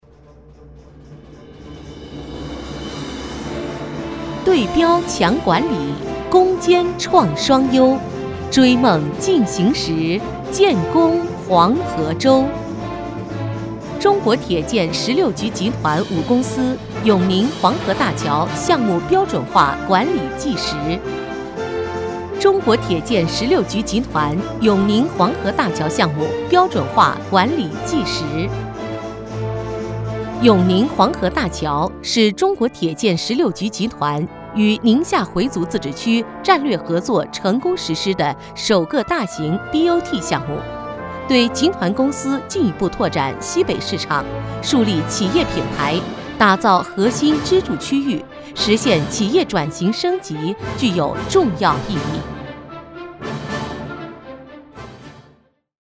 15 女国124_专题_汇报_黄河大桥工作纪实_成熟 女国124
女国124_专题_汇报_黄河大桥工作纪实_成熟.mp3